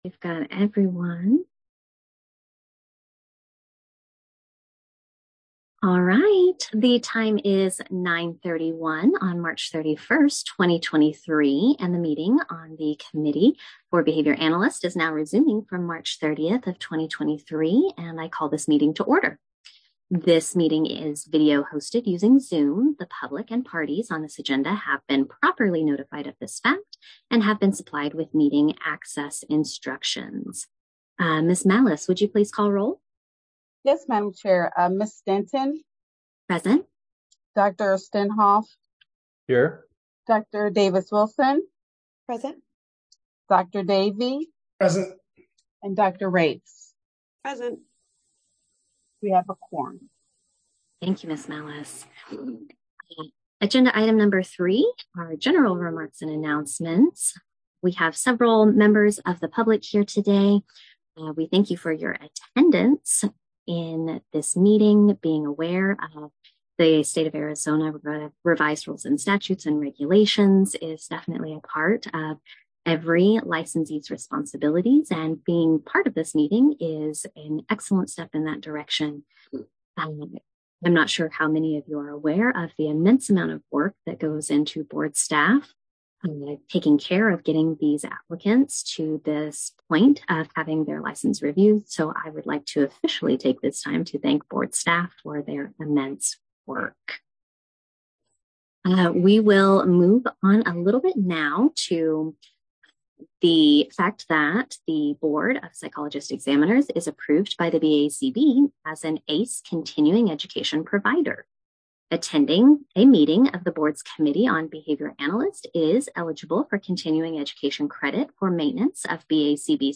Meeting will be held on two days as follows: -Thursday, March 30, 2023, starting at 11:00 a.m. -Friday, March 31, 2023, starting at 9:30 a.m. Members will participate via Zoom both days.